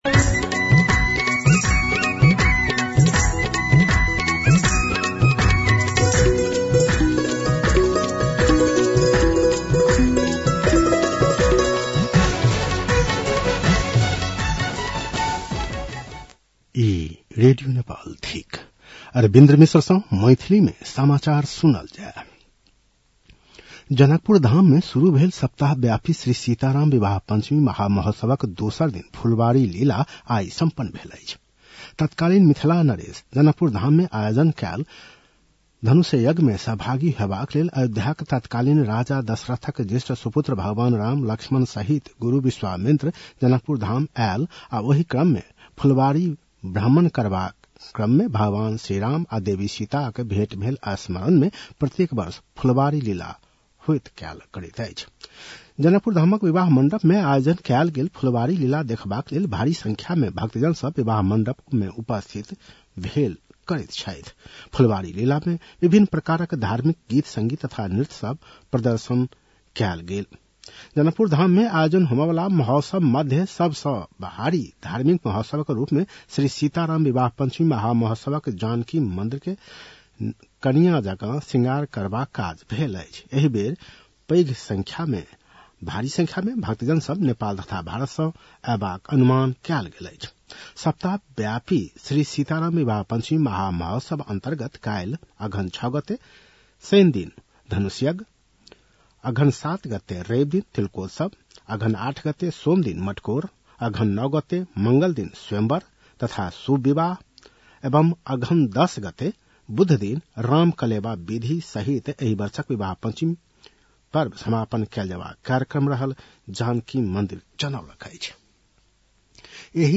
An online outlet of Nepal's national radio broadcaster
मैथिली भाषामा समाचार : ५ मंसिर , २०८२